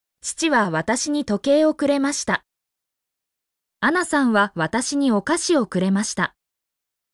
mp3-output-ttsfreedotcom_JO33JAjD.mp3